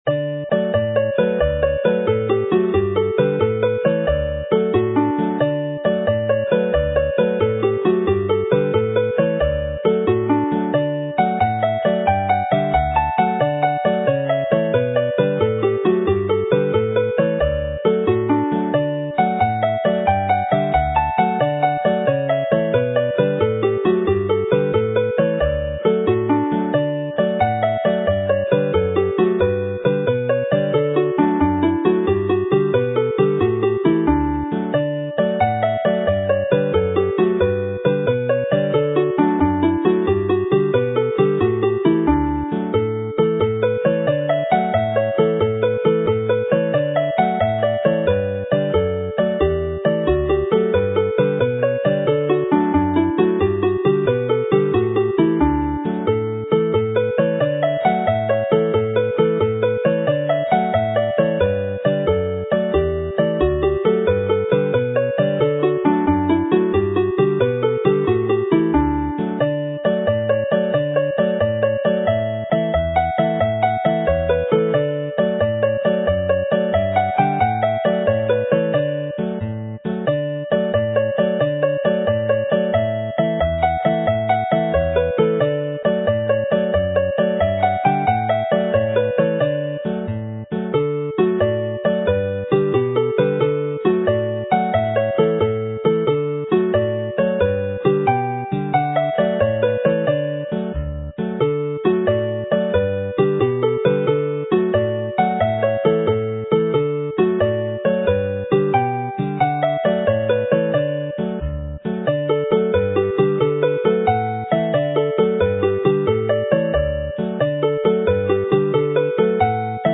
Play the set - once through each tune